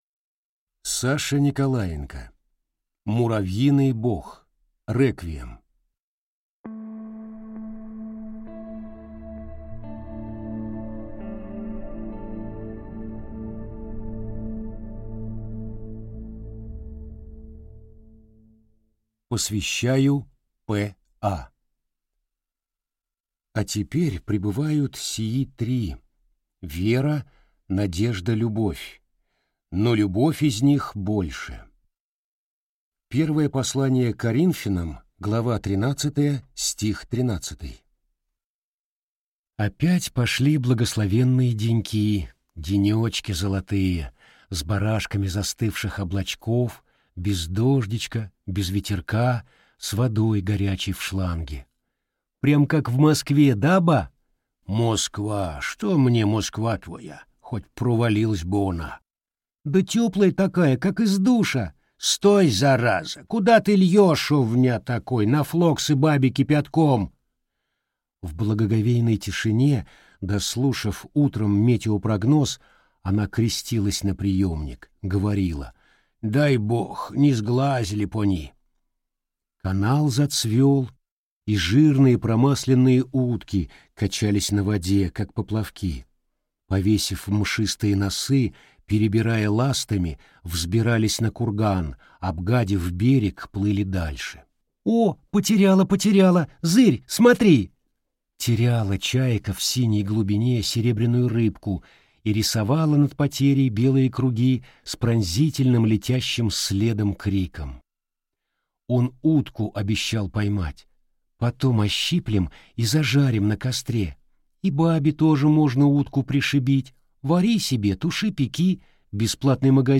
Аудиокнига Муравьиный бог: реквием | Библиотека аудиокниг